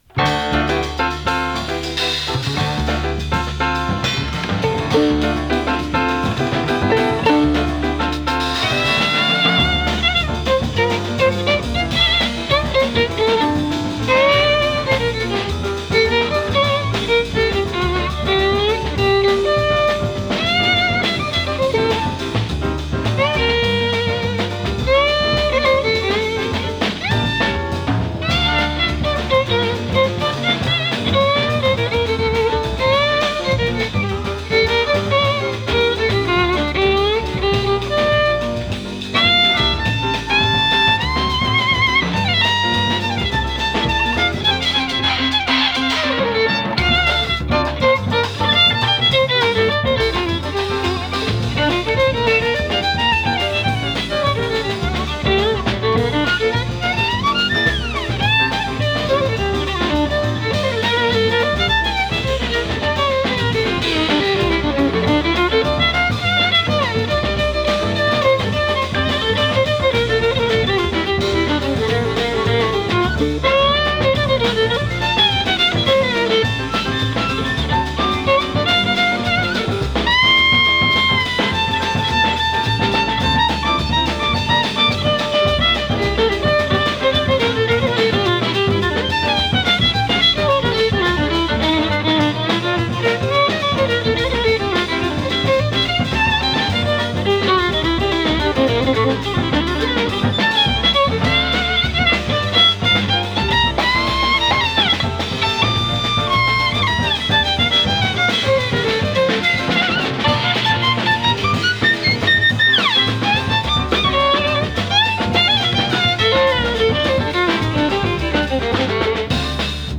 アコースティック スウィング